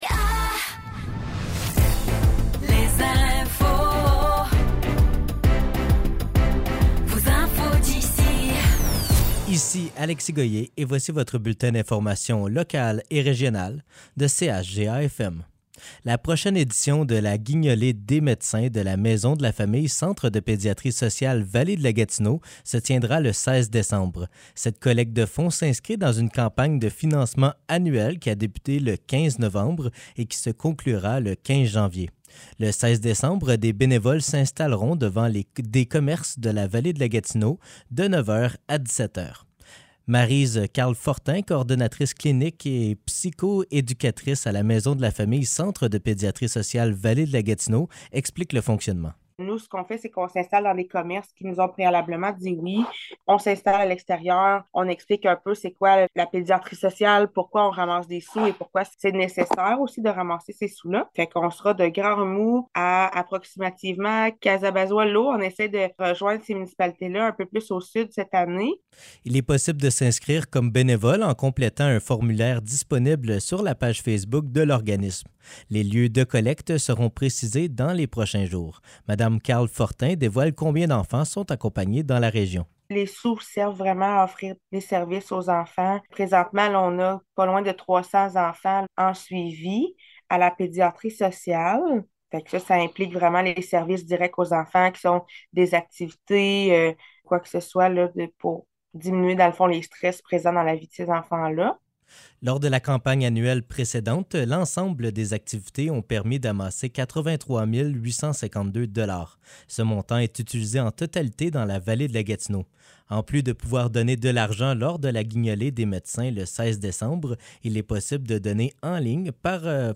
Nouvelles locales - 5 décembre 2023 - 15 h